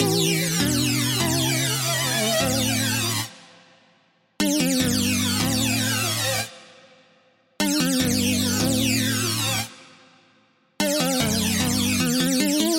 描述：复古合成器循环。
Tag: 150 bpm Electronic Loops Synth Loops 2.15 MB wav Key : A